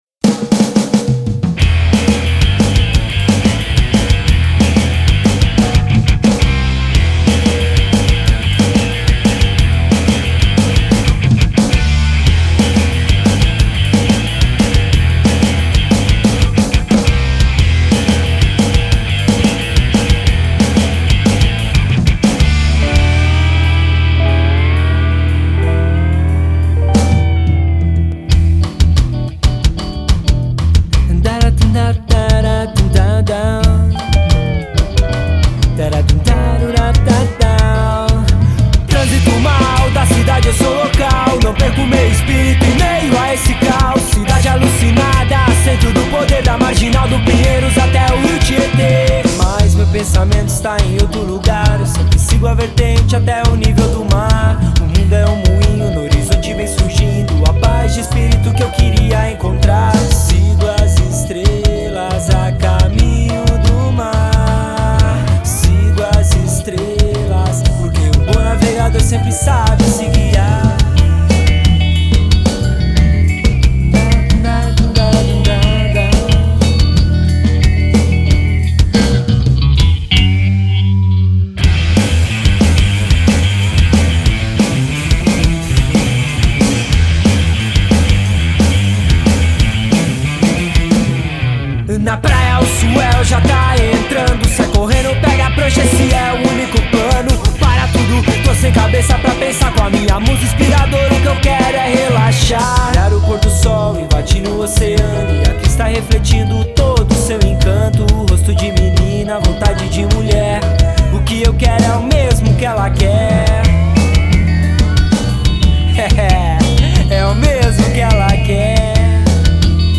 voz
guitarra